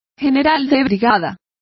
Complete with pronunciation of the translation of brigadier.